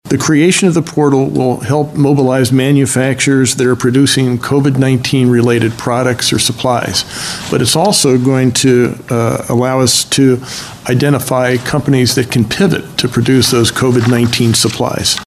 Pennsylvania Governor Tom Wolf says the state has launched the Pennsylvania Manufacturing Call to Action Portal.